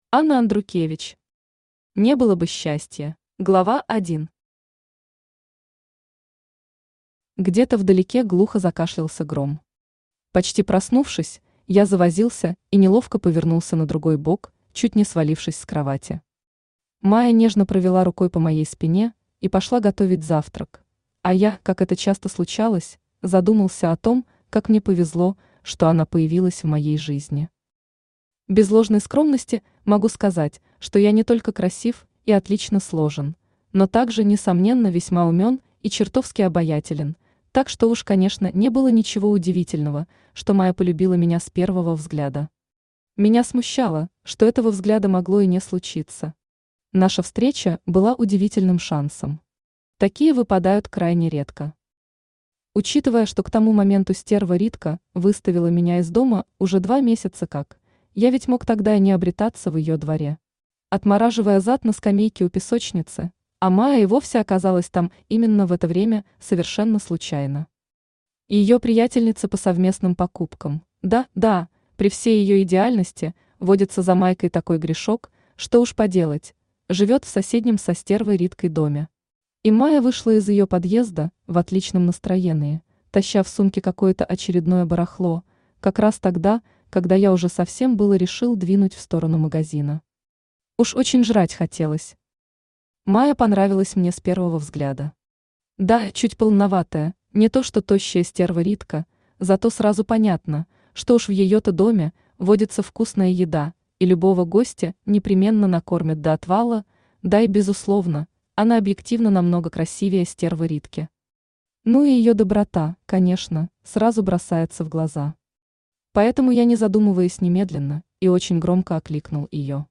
Аудиокнига Не было бы счастья…
Автор Анна Андрукевич Читает аудиокнигу Авточтец ЛитРес.